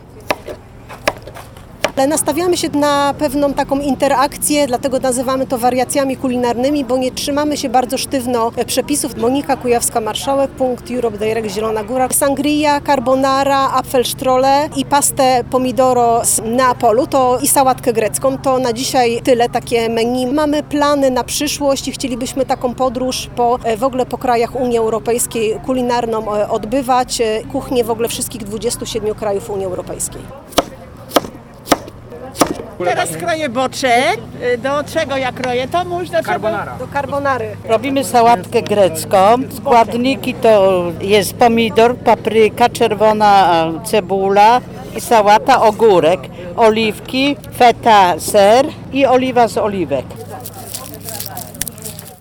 Dziś ponad 30 seniorów uczyło się kuchni śródziemnomorskiej w plenerze. Starsze osoby samodzielnie przygotowywały nieznane im wcześniej potrawy od podstaw.